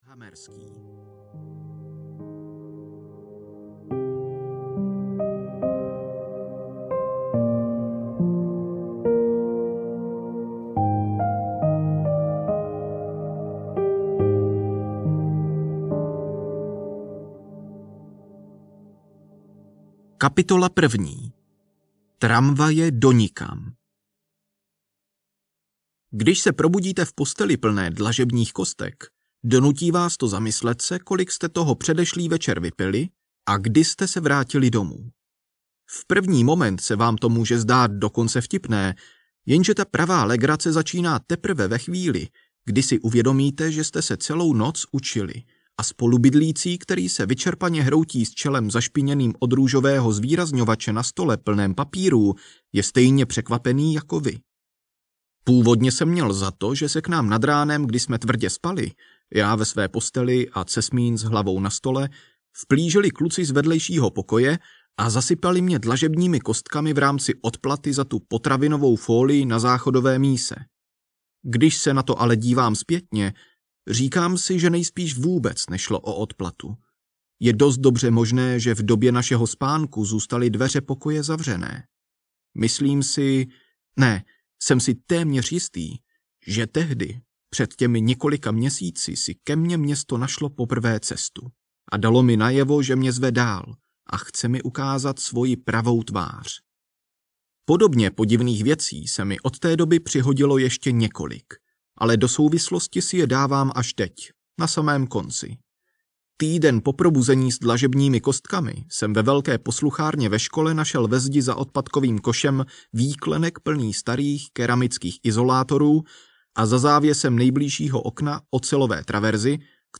Kaziměsti audiokniha
Ukázka z knihy